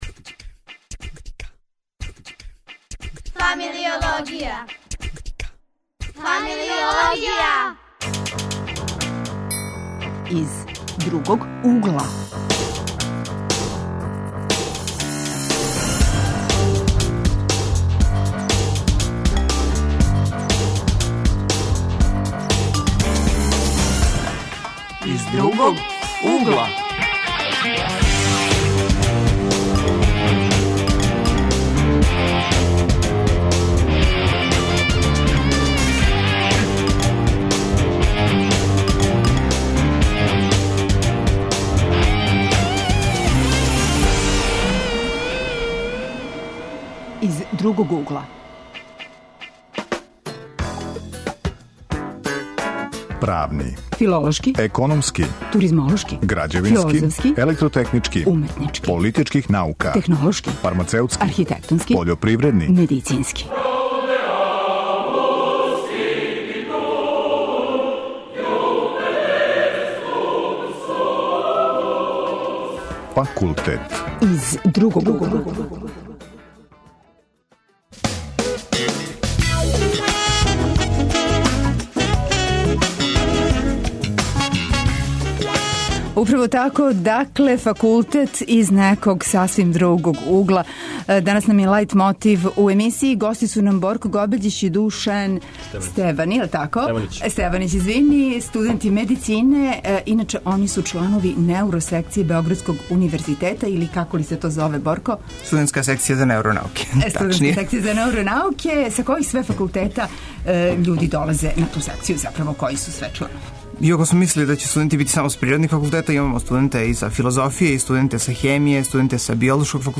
Студирање из другог угла, тема је данашње емисије. Гости су нам студенти београдског универзитета, чланови секције за неуронауке.